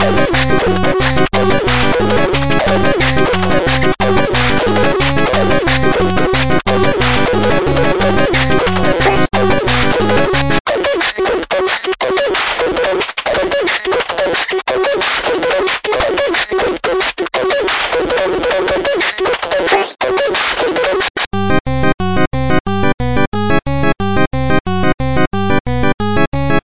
SKAman loop
描述：Basic skapunk rhythmic layer. Base for brass or smt.
distortion loop rock drumloop skacore punk drum drums overdrive percussionloop guitar beat bass reggae rhythmic ska skapunk rhythm music 120bpm
声道立体声